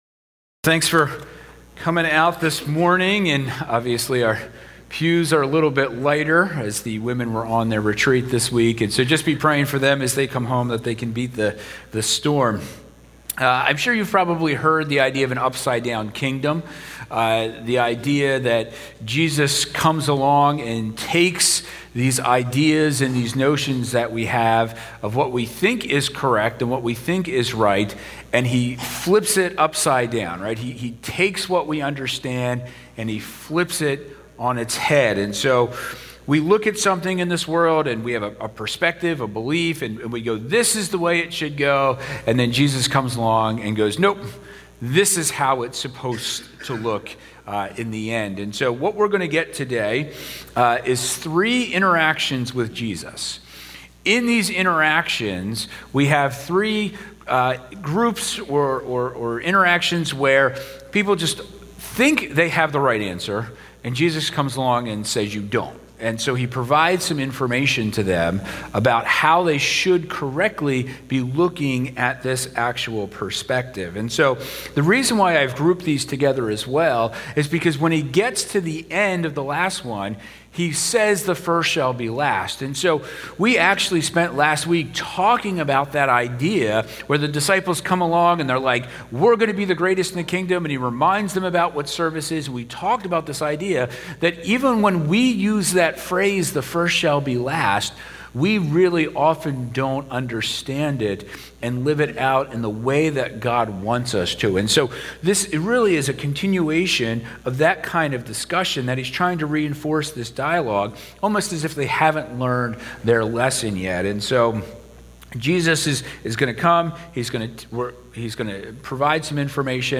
Penn Valley Church Sermons